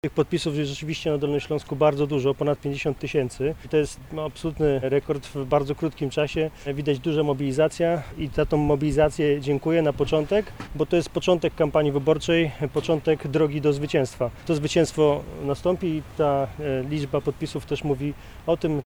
Konferencja prasowa odbyła się z udziałem wszystkich kandydatów i kandydatek KO.
-To początek naszego zwycięstwa, mówił Michał Jaros – przewodniczący dolnośląskiej PO.